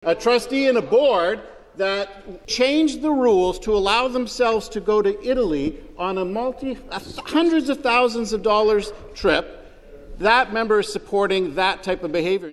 Brady criticized the Minister of Education, Paul Calandra, for attempting to deflect attention during the debate by bringing up an unrelated issue with local trustees.